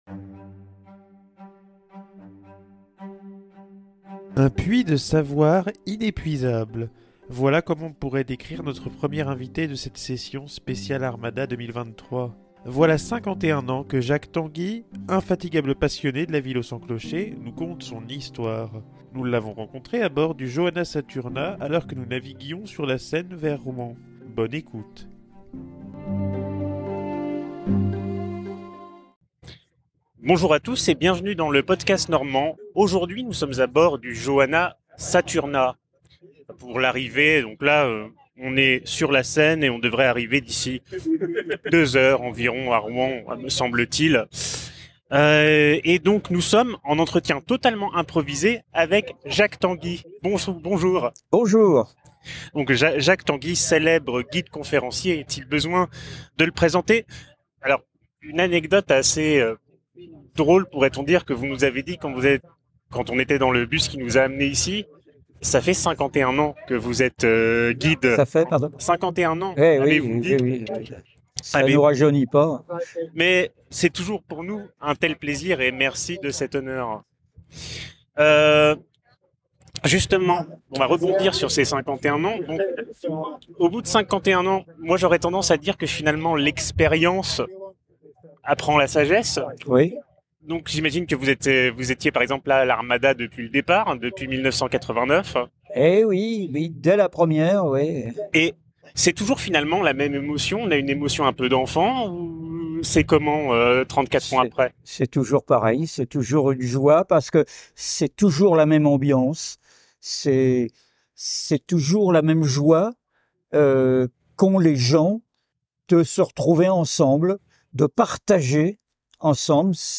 Infatigable passionné de la ville aux cents clochers nous conte son histoire Nous l’avons rencontré à bord du Johanna Saturna, alors que nous navigions sur la Seine vers Rouen, Bonne écoute !Hébergé par Ausha.